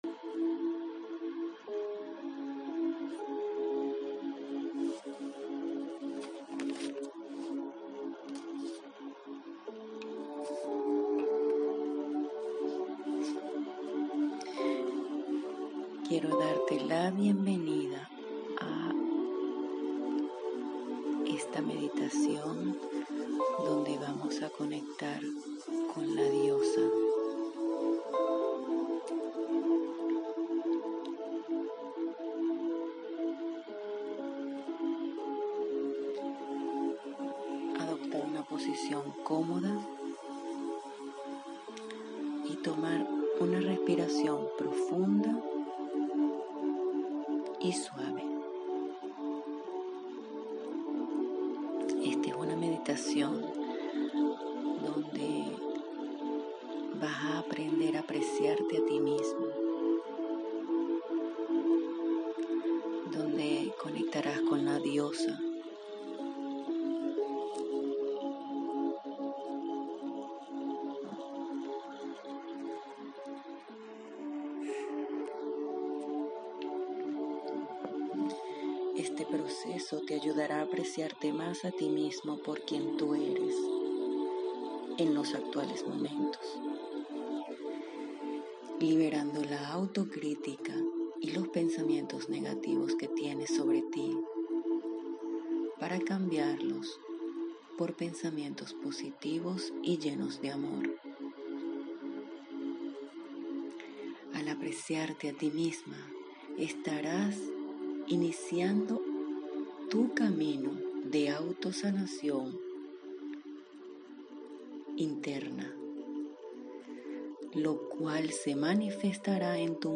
Meditación de regalo para conectar con tu ser de amor y tu diosa